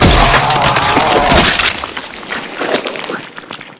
spacewormdie.wav